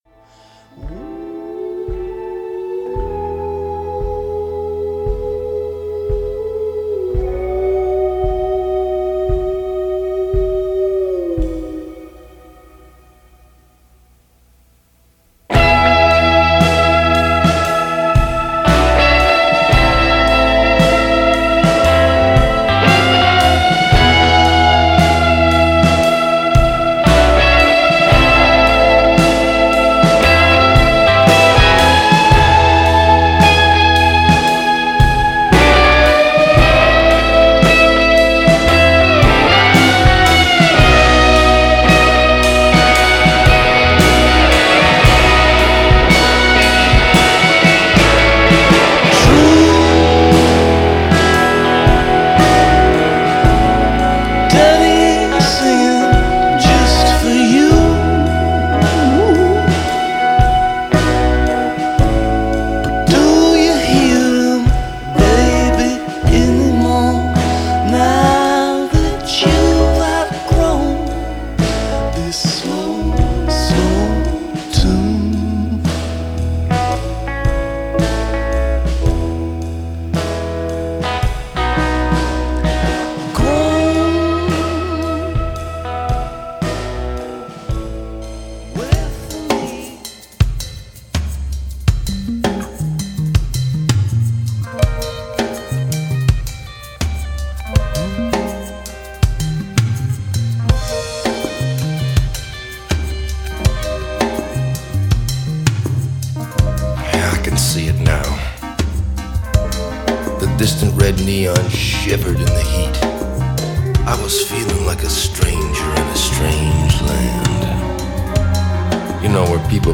that beautiful sax solo